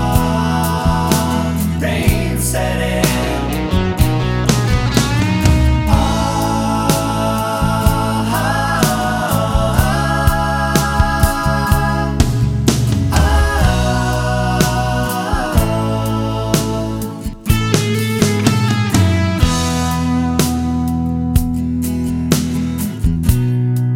No Piano Pop (1970s) 3:49 Buy £1.50